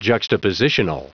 Prononciation du mot juxtapositional en anglais (fichier audio)
Prononciation du mot : juxtapositional